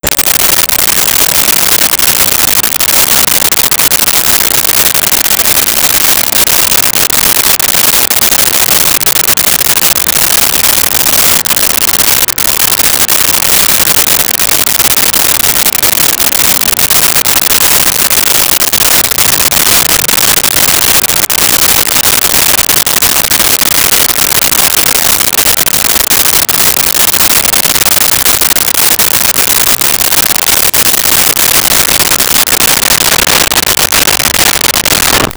Bus Station Exterior
Bus Station Exterior.wav